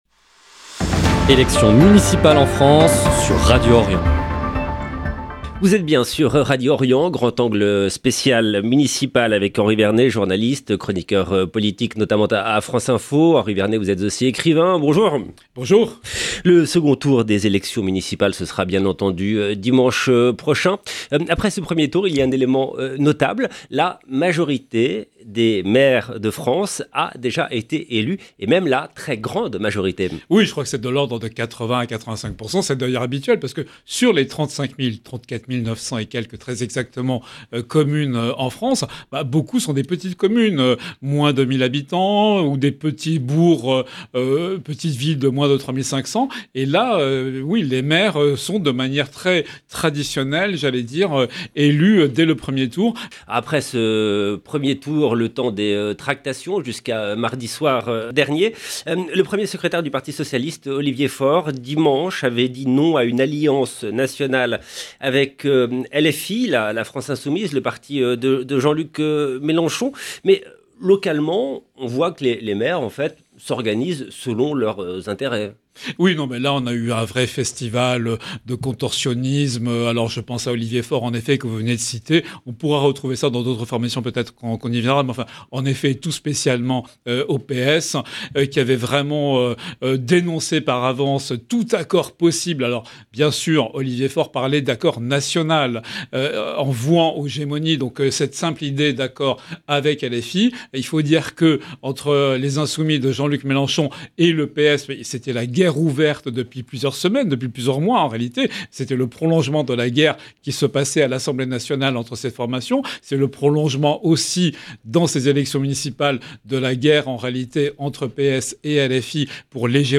Les enjeux du second tour des élections municipales dimanche prochain en France au cœur de cette émission, avec un éclairage sur les rapports de force, les alliances entre les deux tours et les dynamiques locales qui pourraient redessiner le paysage municipal. Eclairage